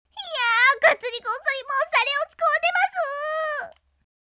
SAMPLE VOICE